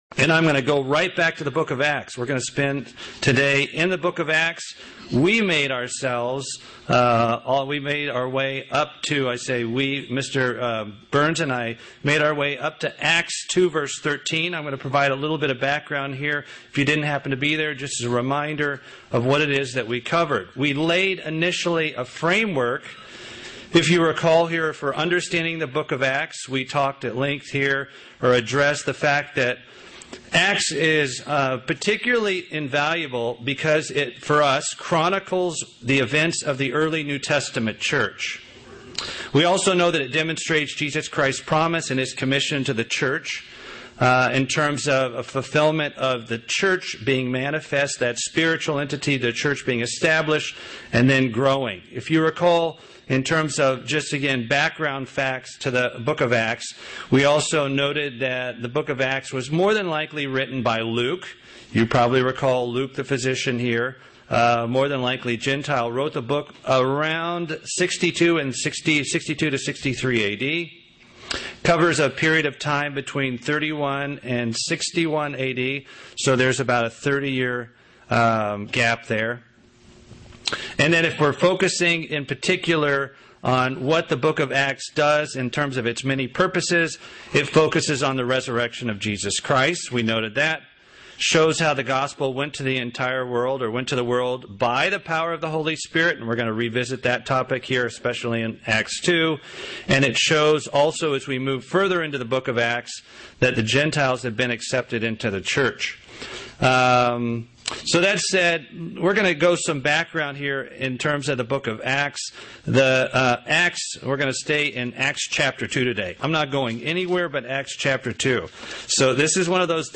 Given in Atlanta, GA
Early church practices of the newly formed New Testament church UCG Sermon Studying the bible?